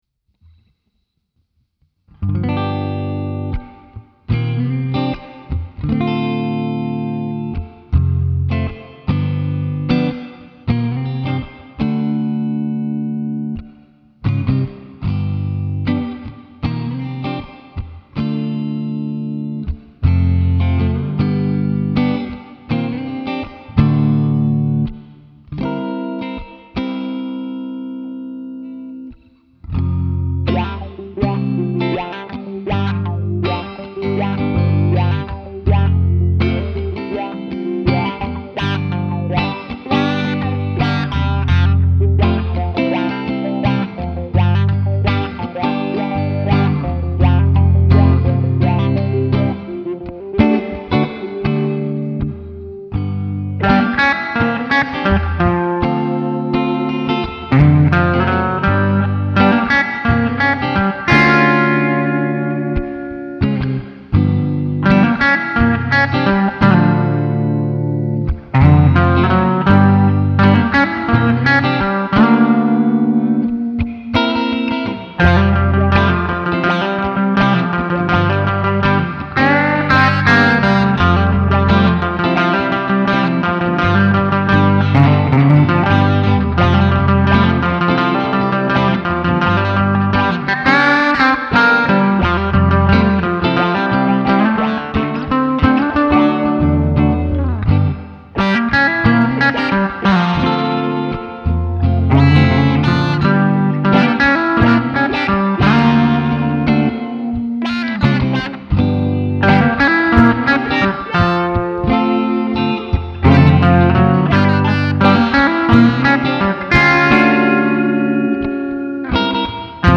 Otra nueva canción que muestra los sonidos que se pueden grabar con el V-amp 2.